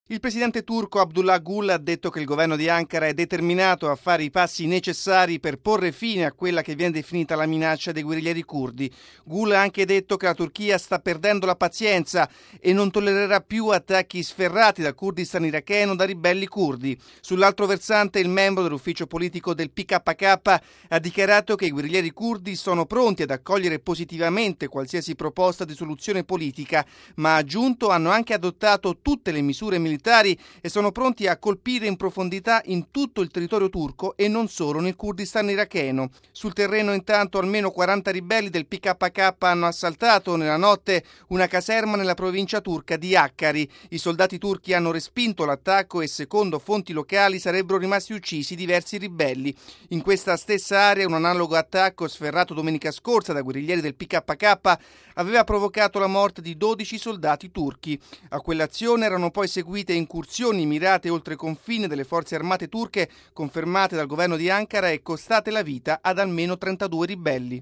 Il nostro servizio: